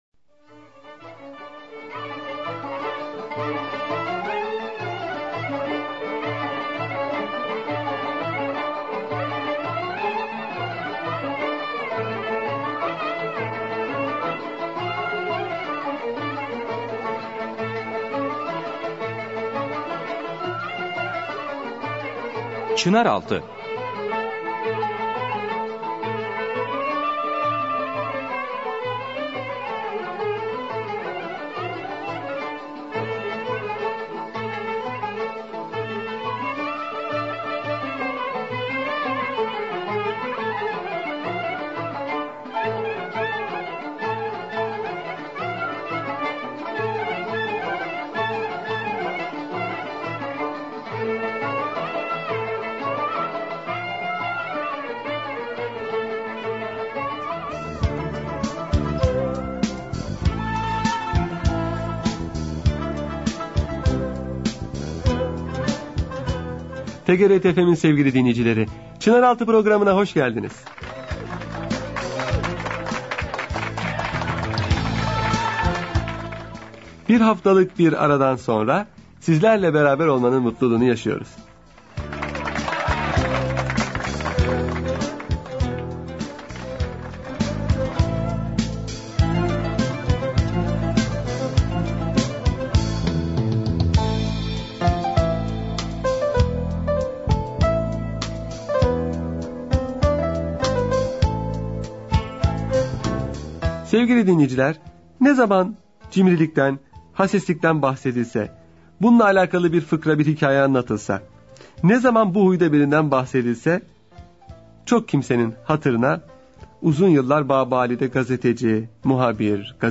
Radyo Programi - Cimrilik